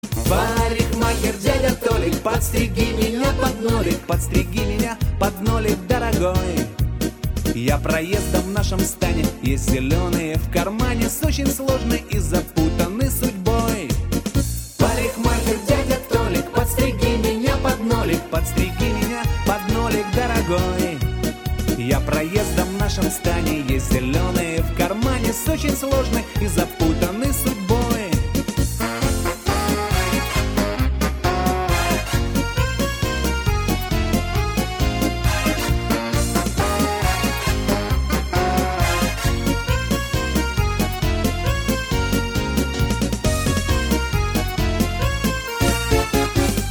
• Качество: 128, Stereo
мужской вокал
русский шансон
Блатняк